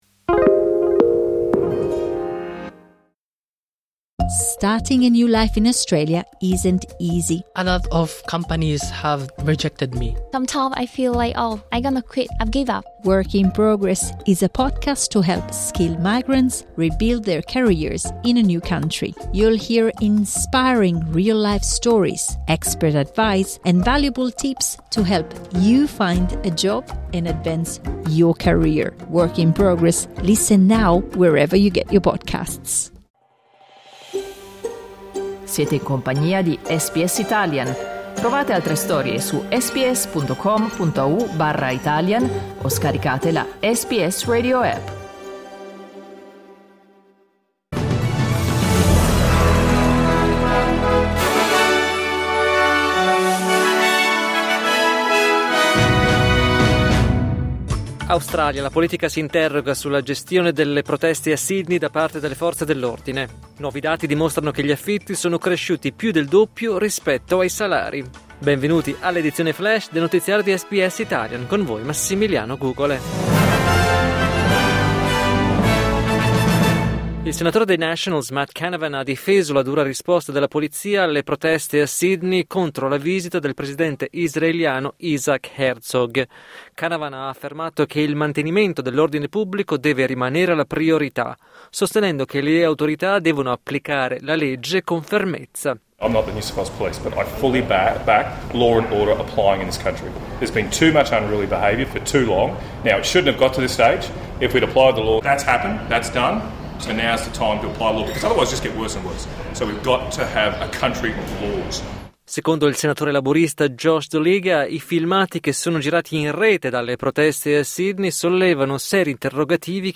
News flash mercoledì 11 febbraio 2026
L’aggiornamento delle notizie di SBS Italian.